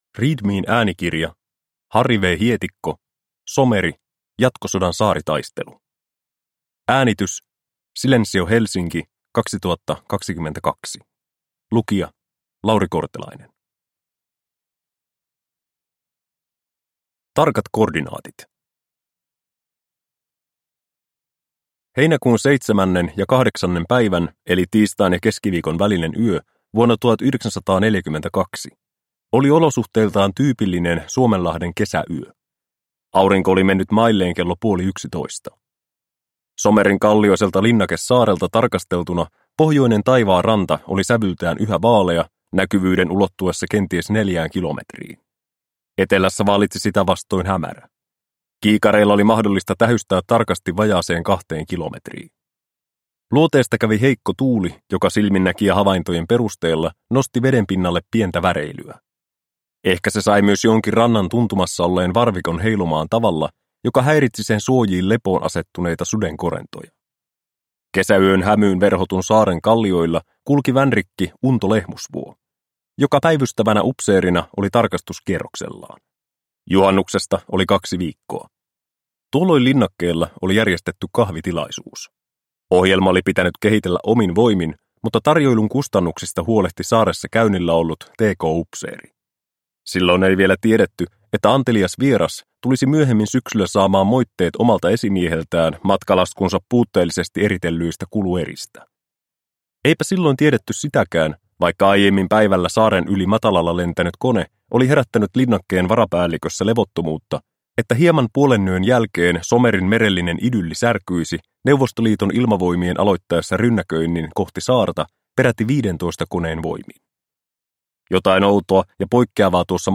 Someri - Jatkosodan saaritaistelu – Ljudbok